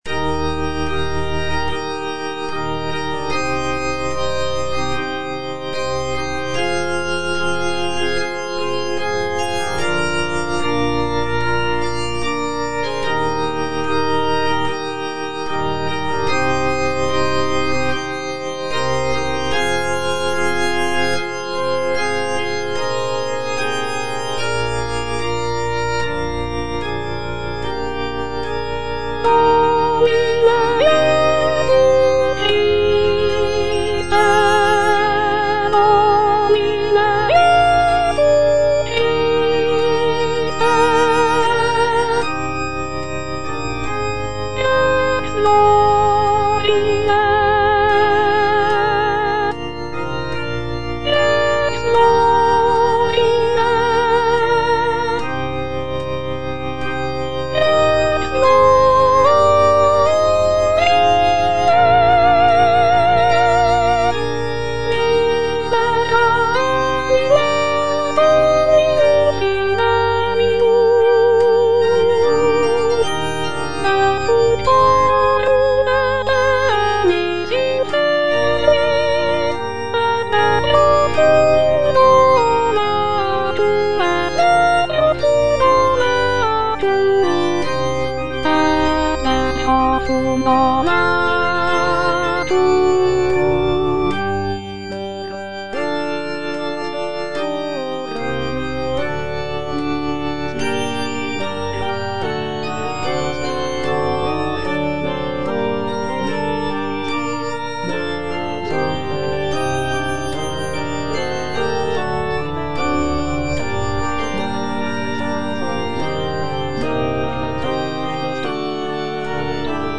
Soprano (Voice with metronome
is a sacred choral work rooted in his Christian faith.